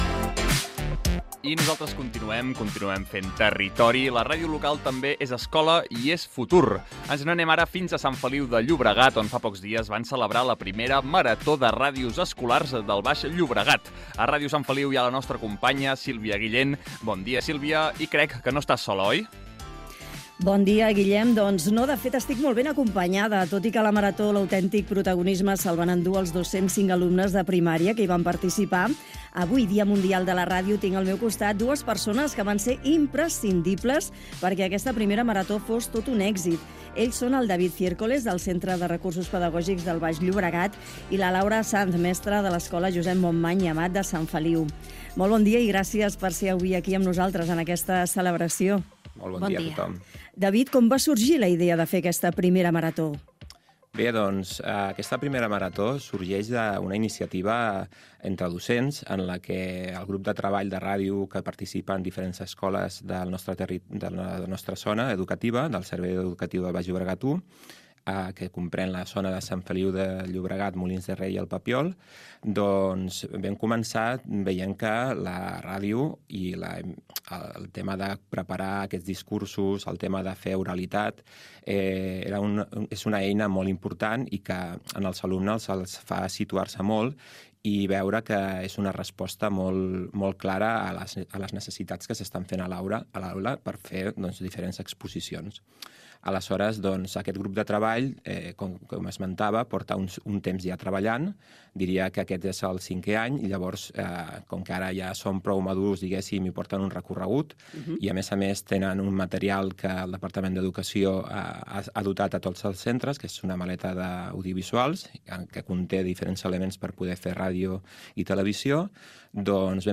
Entrevista sobre la primera marató radiofònica de ràdios escolars del Baix Llobregat
Entreteniment